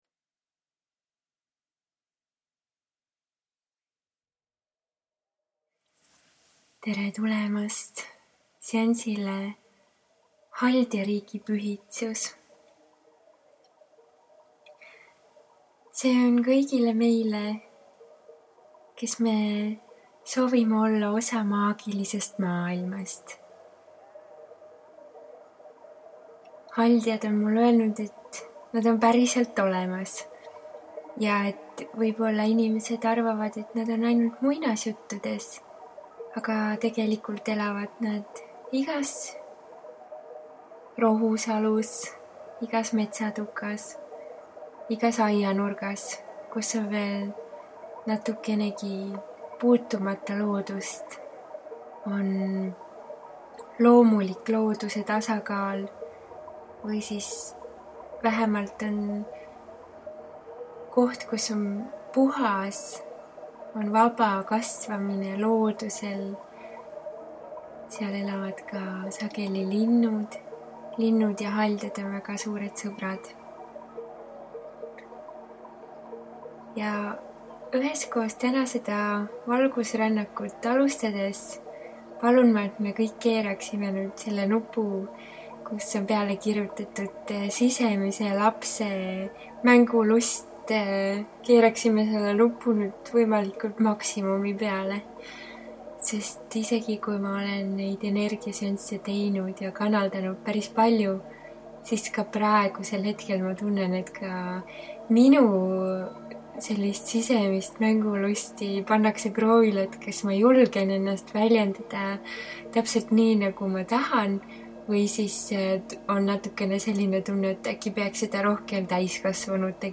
SIIT LEHELT SAAD KUULATA: MEDITATSIOON - MAAGILINE SISERÄNNAK HALDJARIIGI PÜHITSUS salvestatud aastal 2015   Rännak üle Haldjasilla on sügava ühenduse äratamiseks Maagilise maailmaga.